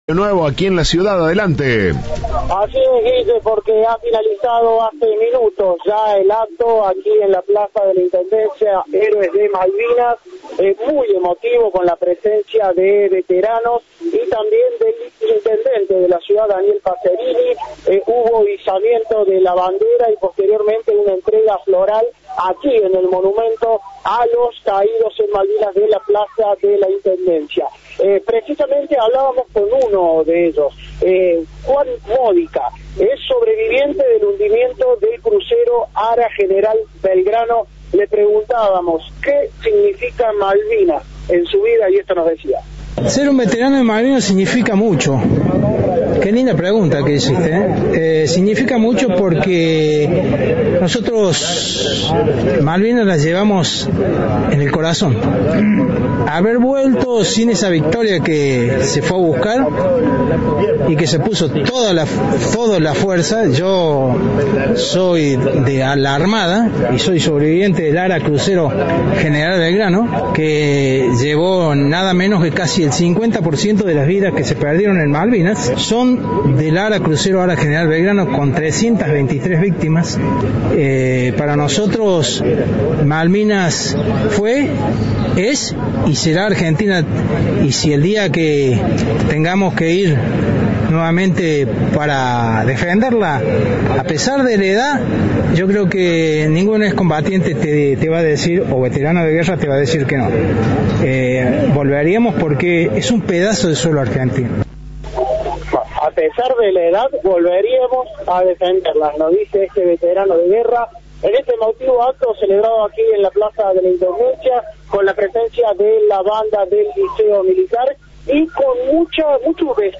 Conmovedor acto en Córdoba por los Héroes de Malvinas